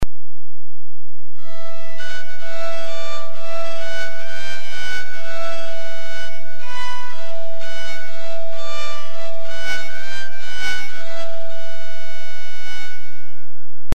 BOWED PSALTERY
Bowed Psaltery Sound Clips
There is no fretting: each string is simply tuned to a different note (the scale of C on one side with the "accidentals" - the sharps and the flats - on the other). It gives a delightfully high, echoing, ethereal sound - or, if you don't like it, it sets your teeth on edge!